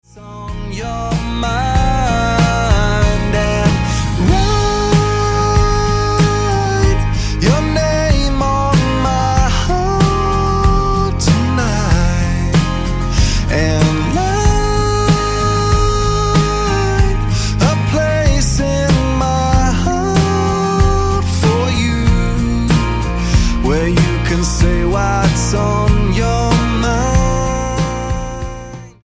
Roots/Acoustic
Style: Pop